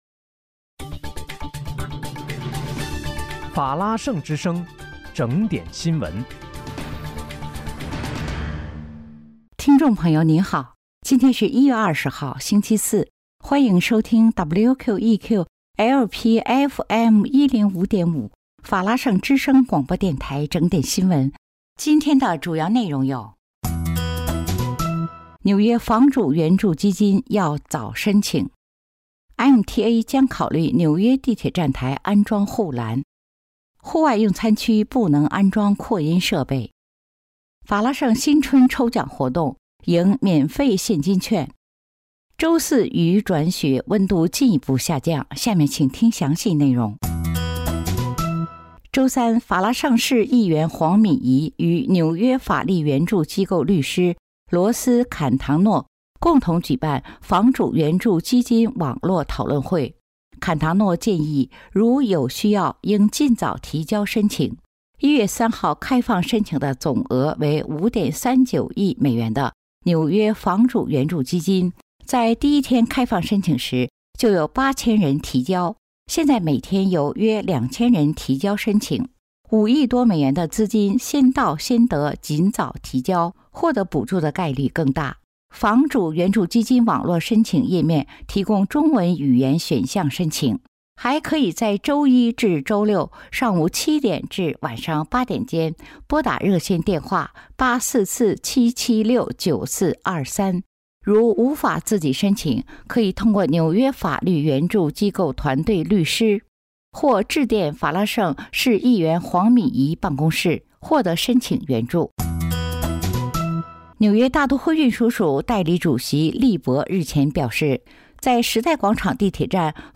1月20日（星期四）纽约整点新闻
听众朋友您好！今天是1月20号，星期四，欢迎收听WQEQ-LP FM105.5法拉盛之声广播电台整点新闻。